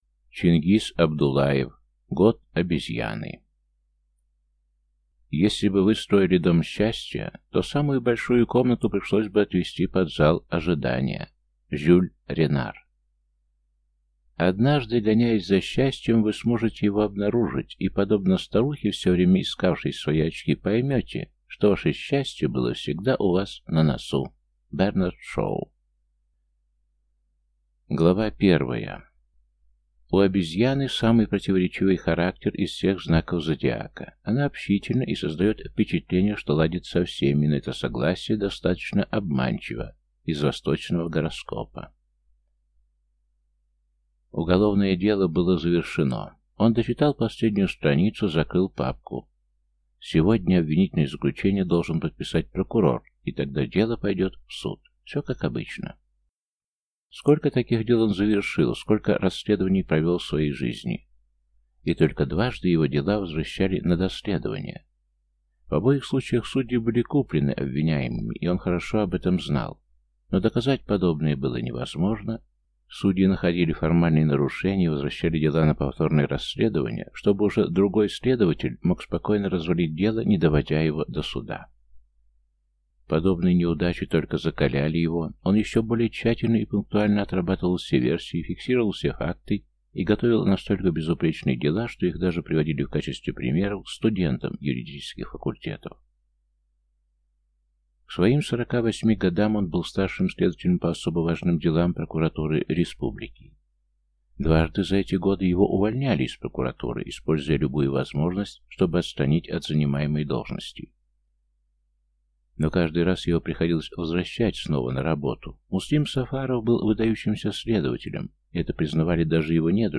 Аудиокнига Год обезьяны | Библиотека аудиокниг